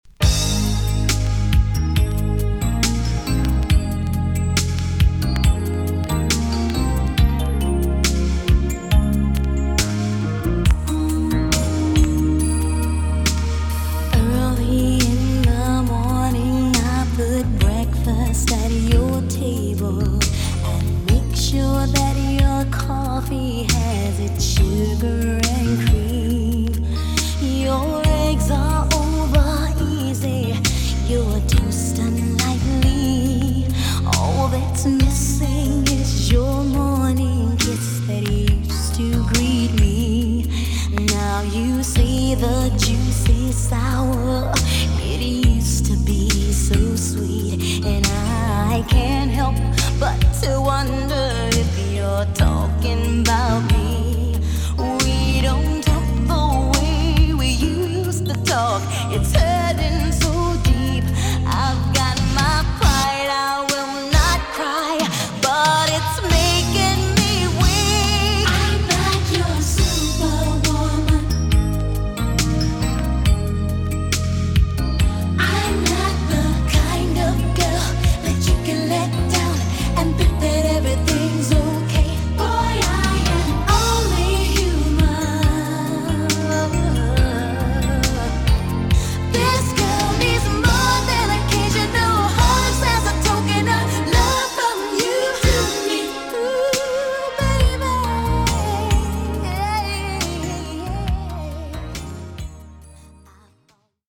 TOP >JAMAICAN SOUL & etc
EX- 音はキレイです。
NICE SLOW JAM TUNE!!